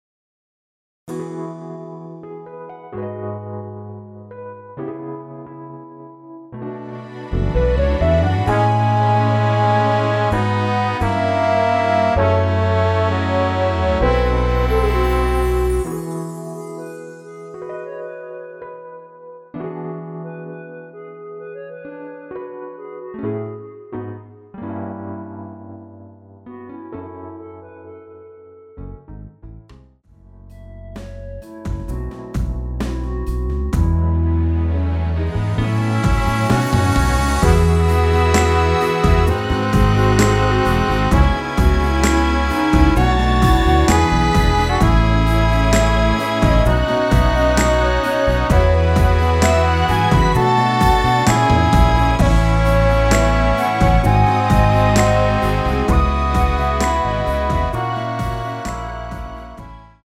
원키에서(+1)올린 멜로디 포함된 MR입니다.(미리듣기 참조)
◈ 곡명 옆 (-1)은 반음 내림, (+1)은 반음 올림 입니다.
앞부분30초, 뒷부분30초씩 편집해서 올려 드리고 있습니다.